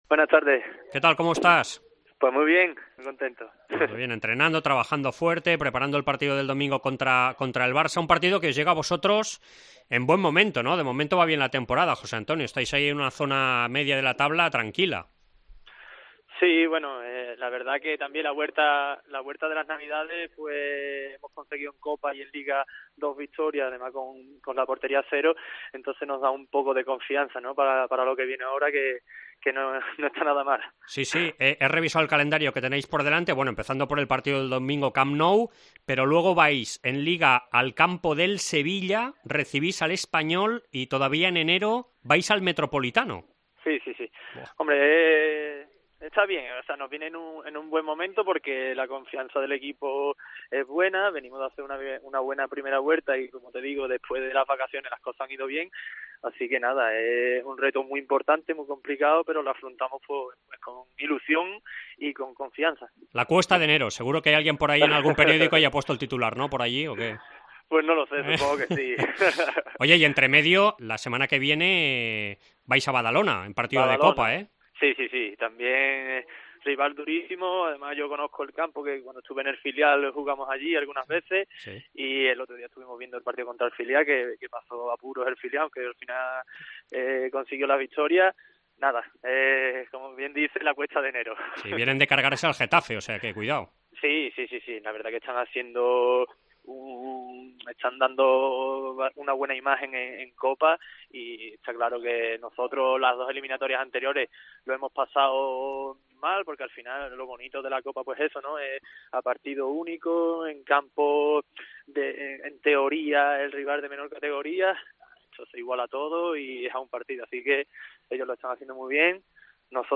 AUDIO: Entrevista a l'ex-jugador del FC Barcelona que diumenge visita el Camp Nou amb el Granada en el debut de Quique Setién